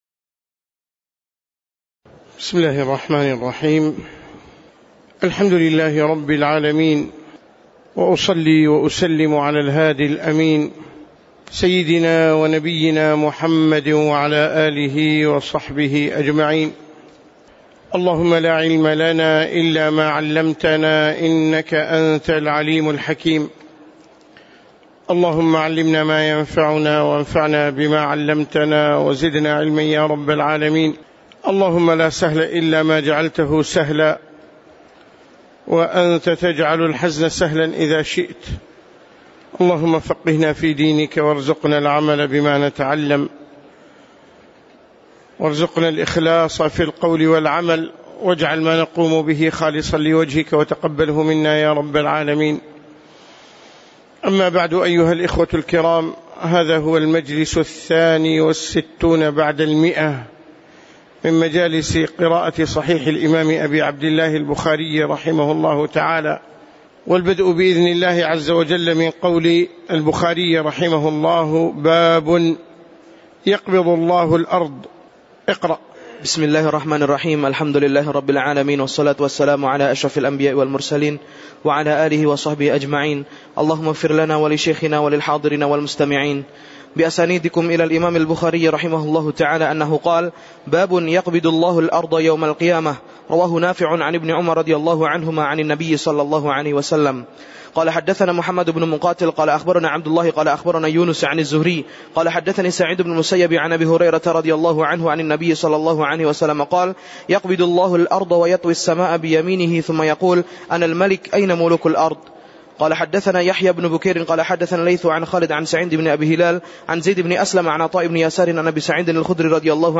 تاريخ النشر ٦ صفر ١٤٣٩ هـ المكان: المسجد النبوي الشيخ